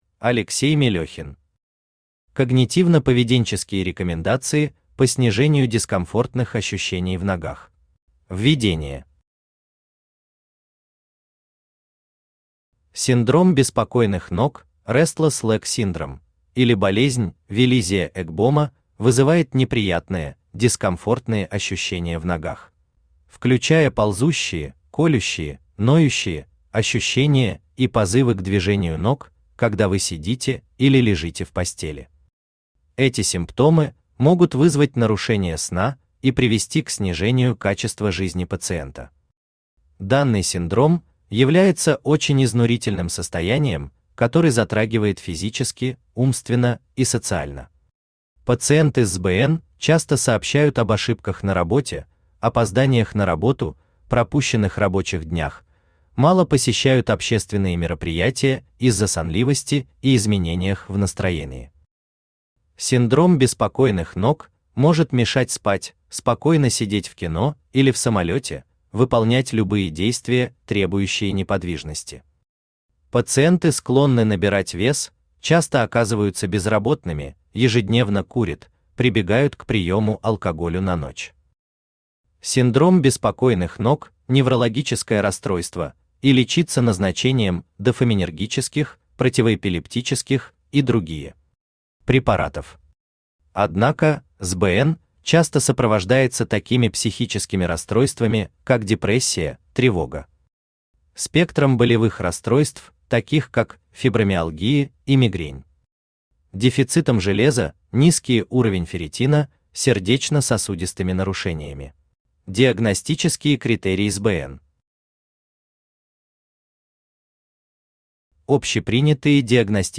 Аудиокнига Когнитивно-поведенческие рекомендации по снижению дискомфортных ощущений в ногах | Библиотека аудиокниг